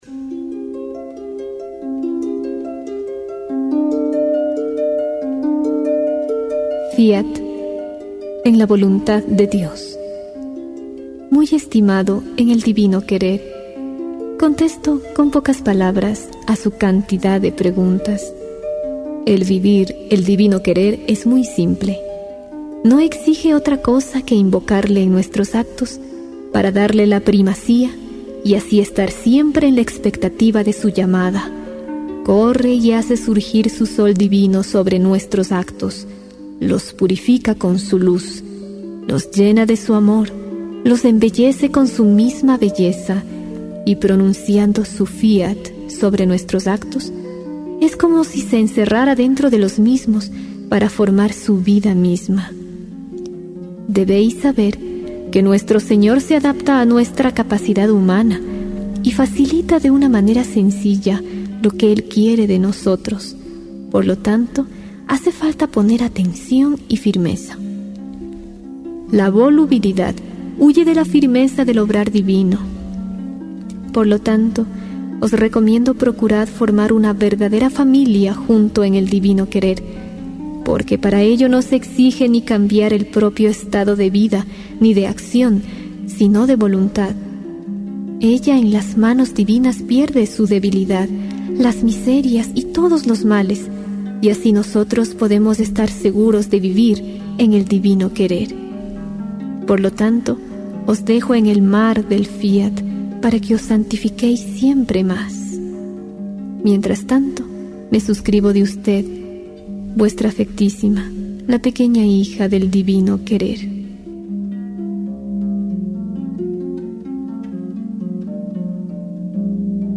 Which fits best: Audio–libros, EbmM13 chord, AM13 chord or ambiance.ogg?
Audio–libros